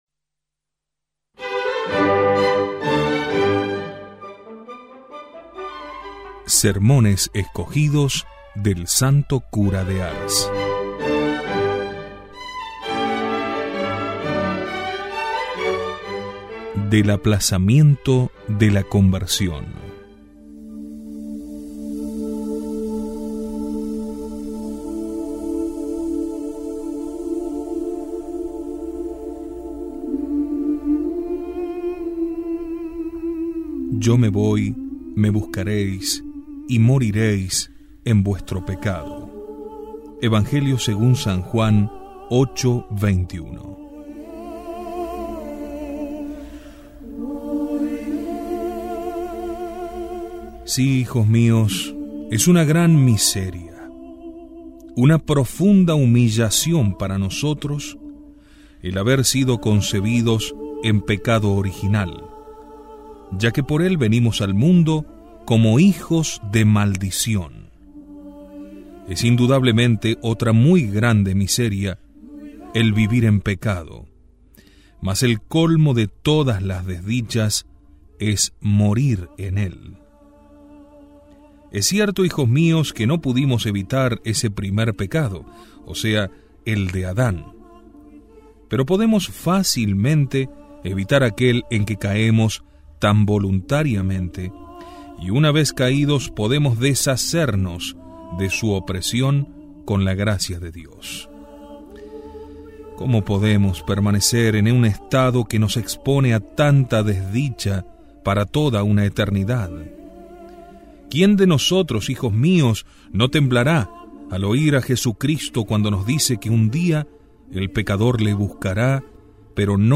Audio–libros
Sermon-del-Santo-Cura-de-Ars-Aplazamiento-de-la-conversion.mp3